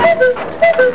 cuckoo.wav